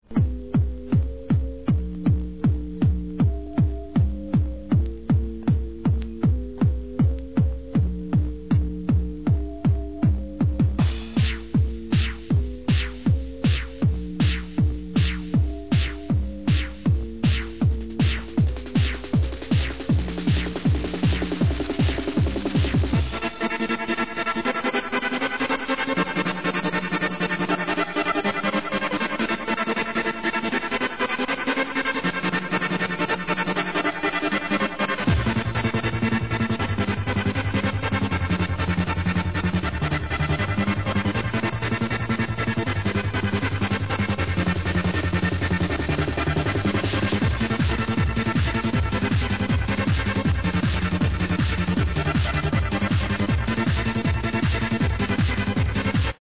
Question old trance tune...